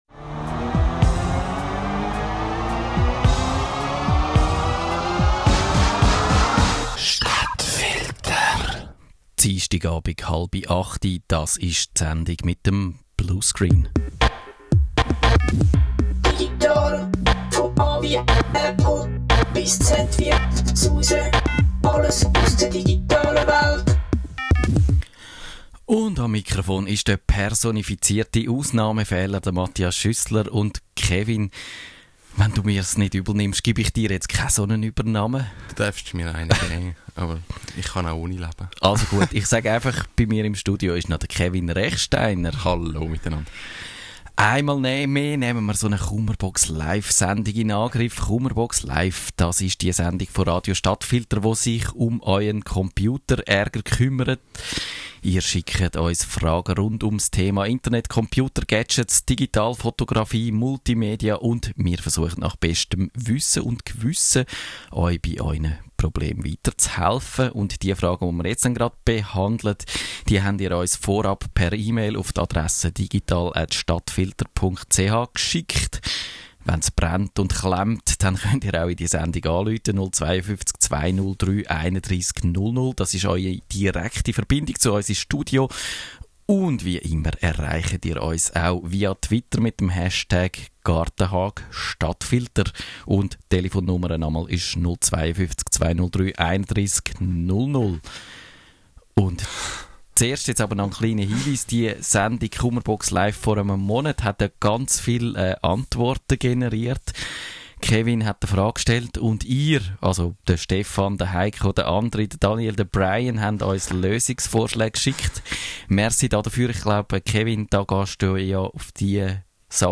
Die Themen der heutigen Kummerbox Live-Sendung haben wir zum Grossteil Microsoft und den diversen Ungereimtheiten in Outlook, Office und in Windows zu verdanken: Ausserdem: Wie fängt man im Web Konzertmitschnitte ein und was tut man mit zu kleinen Festplatten? Und wir starten einen Kummerbox-Live-Spiess-umgedreht-Aufruf: Wie hackt man die Living-Color-Lampe von Philips?